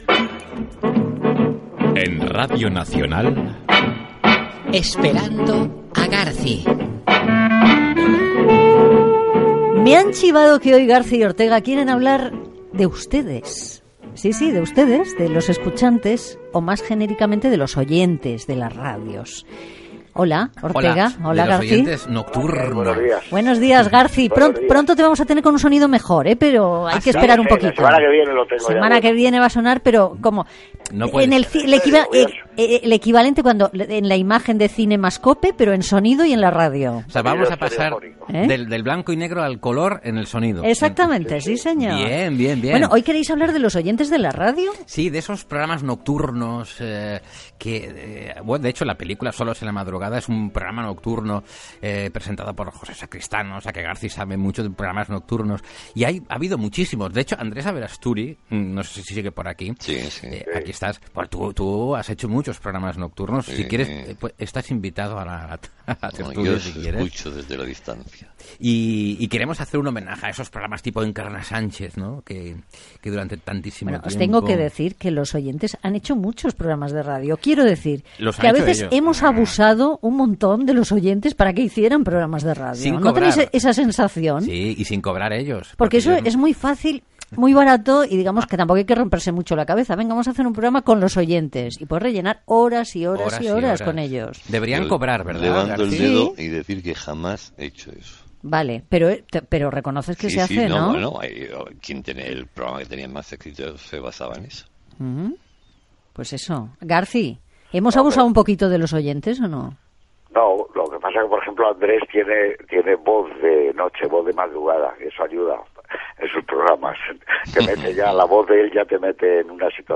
Secció "Esperando a Garci" dedicada als oïdors de la ràdio nocturna Gènere radiofònic Entreteniment